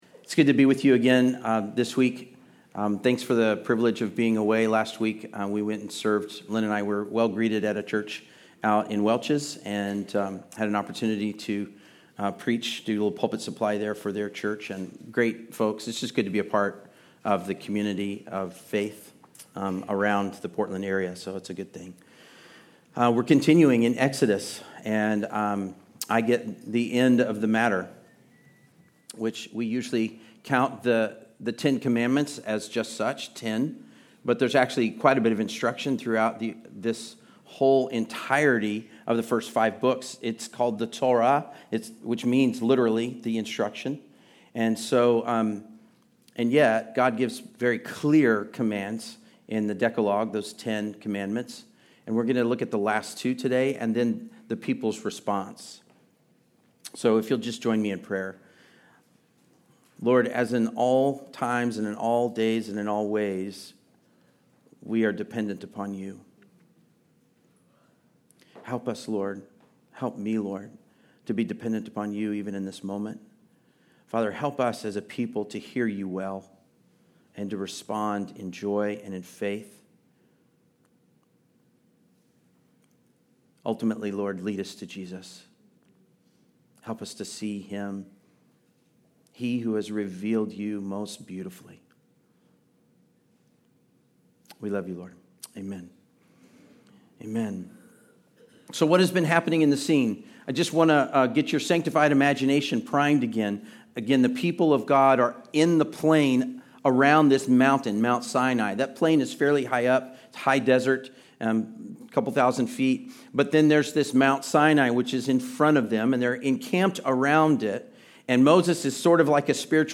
Passage: Exodus 20:16-21 Service Type: Sunday Service